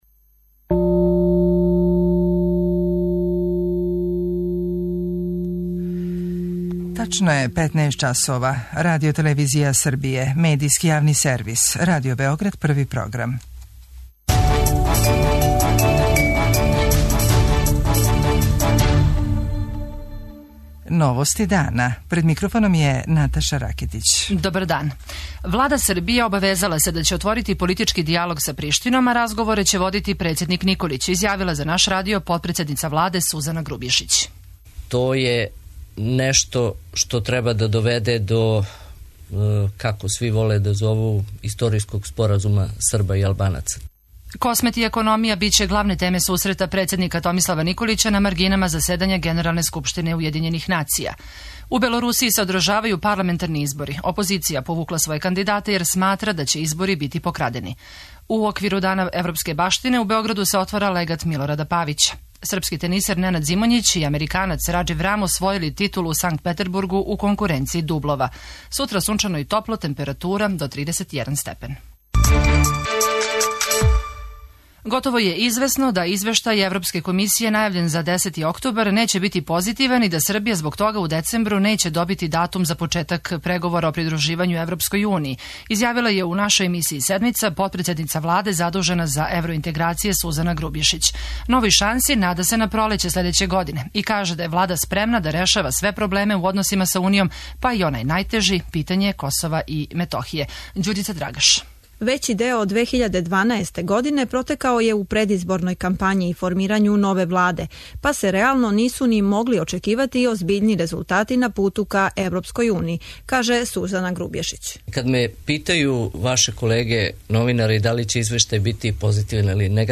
О томе за Новости дана говори министар Селаковић.
преузми : 14.63 MB Новости дана Autor: Радио Београд 1 “Новости дана”, централна информативна емисија Првог програма Радио Београда емитује се од јесени 1958. године.